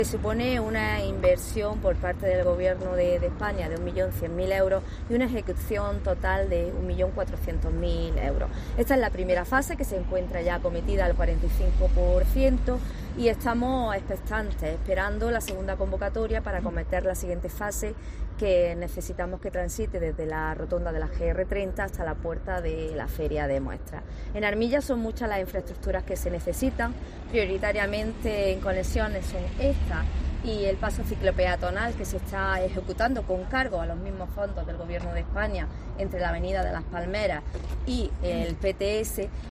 Loli Cañavate, alcaldesa de Armilla